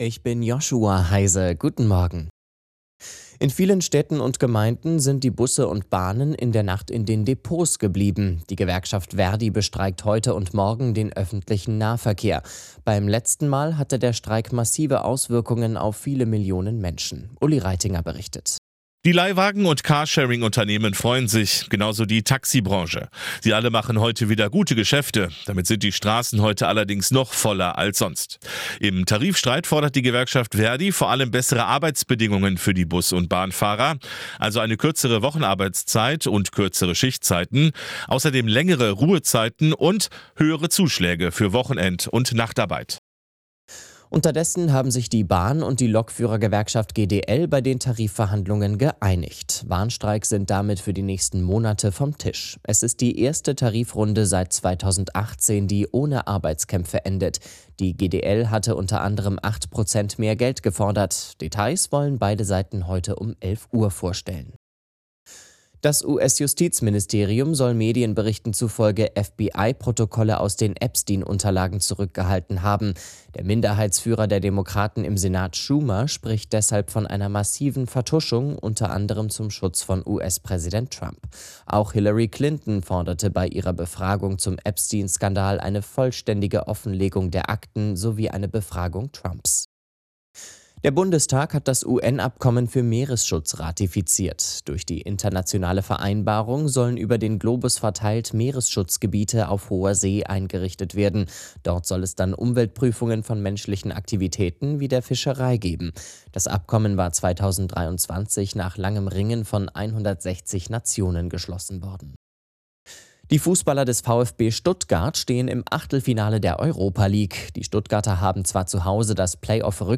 Die aktuellen Nachrichten zum Nachhören